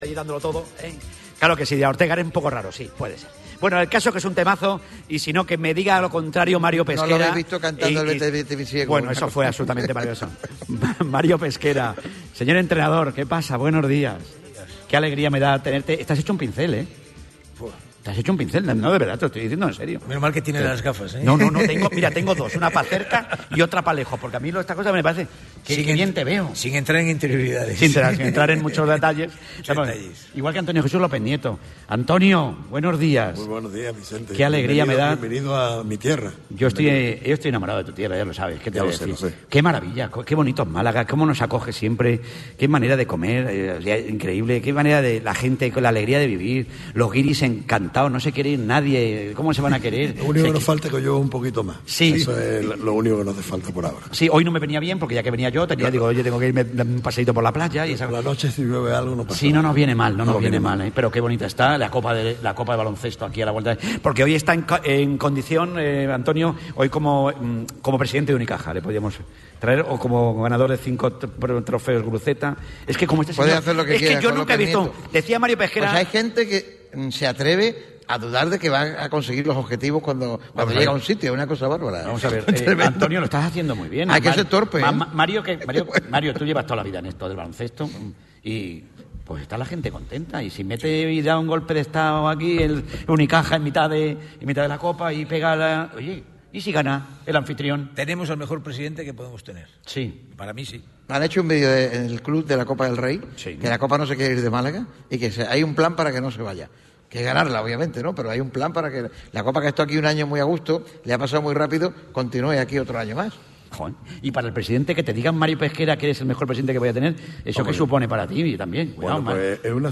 El premio fue otorgado este miércoles 14 de febrero, en el auditorio Edgar Neville, de la Diputación provincial de Málaga.